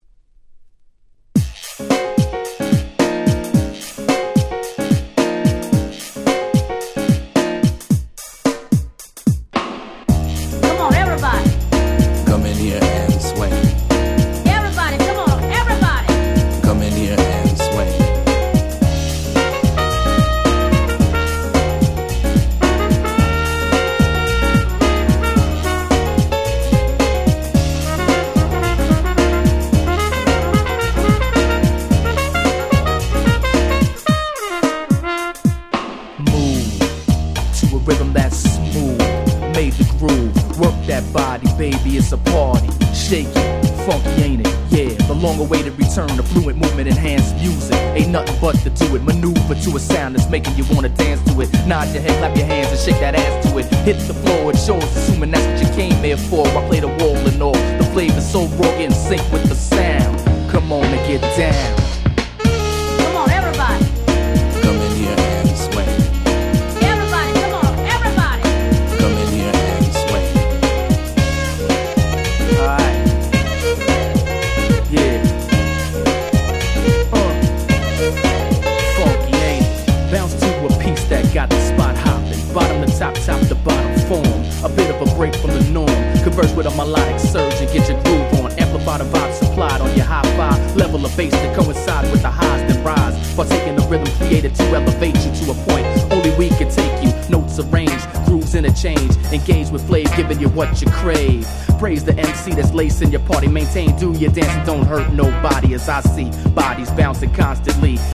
97' Super Nice Acid Jazz !!